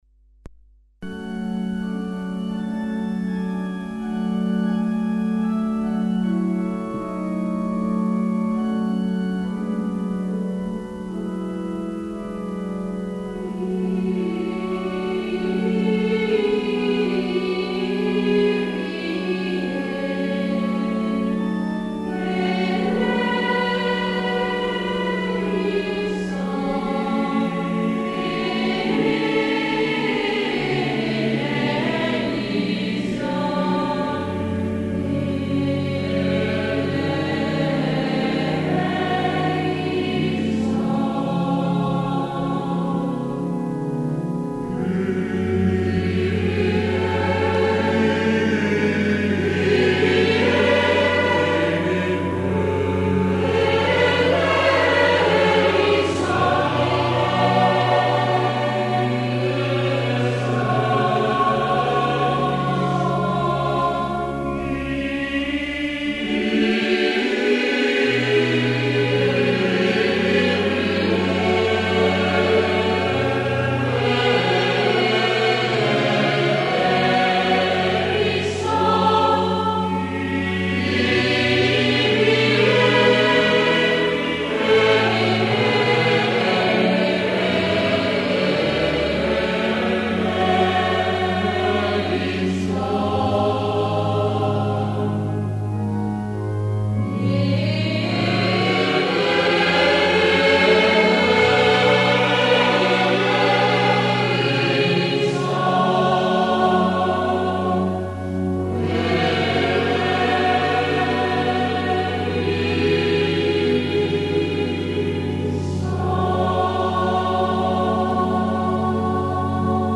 Messa in Flore Mater - Kyrie (Recorded in Seregno 1982